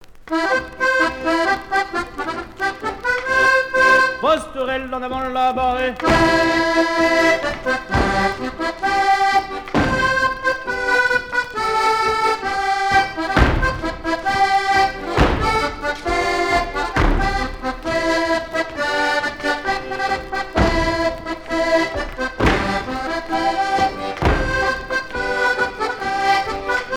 danse : quadrille : pastourelle
Pièce musicale éditée